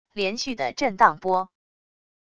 连续的震荡波wav音频